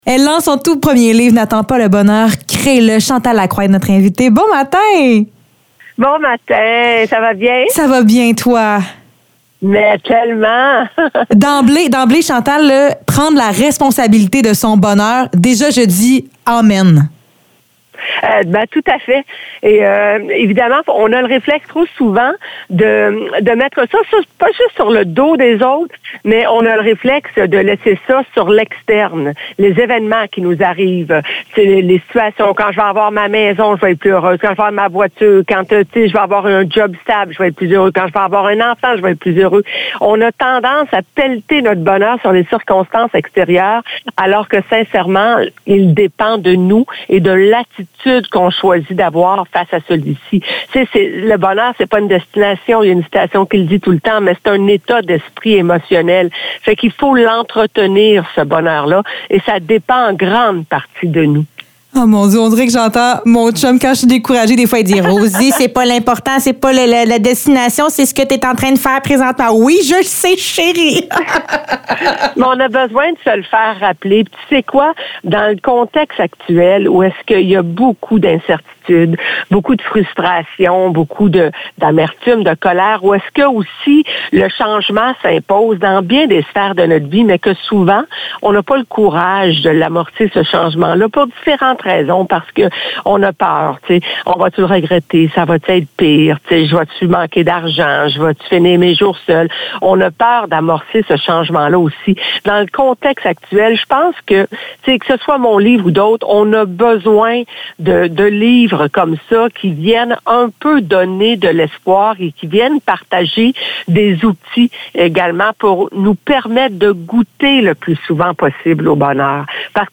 Entrevue avec Chantal Lacroix